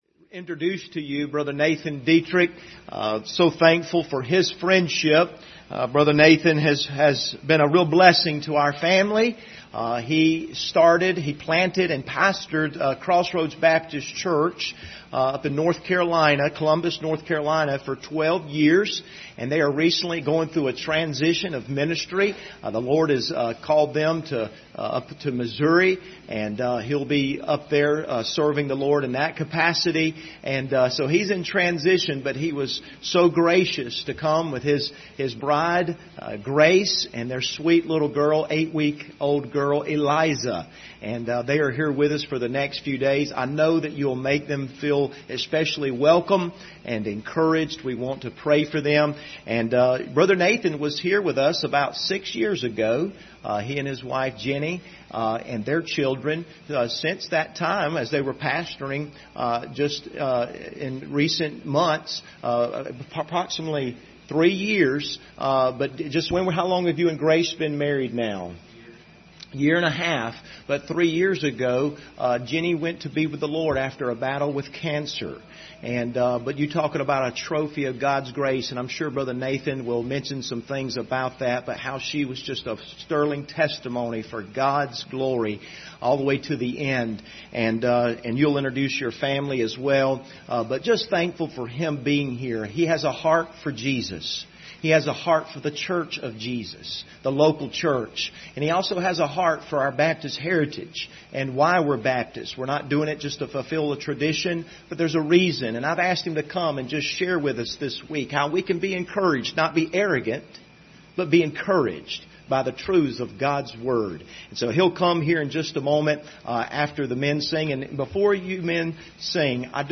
Passage: Matthew 16:16-18 Service Type: Sunday Morning